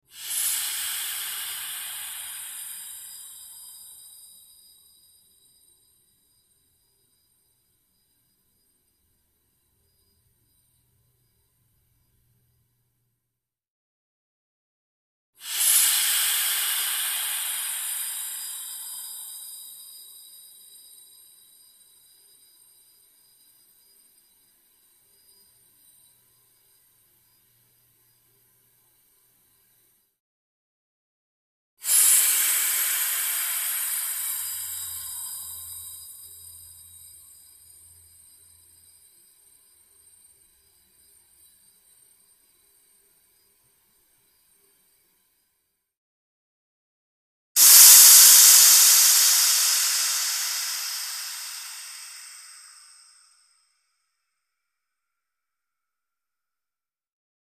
Oxygen Tank; Releases 2; Four Long, Slowly Tapering Oxygen Release; Close Perspective. Pressurized Gas.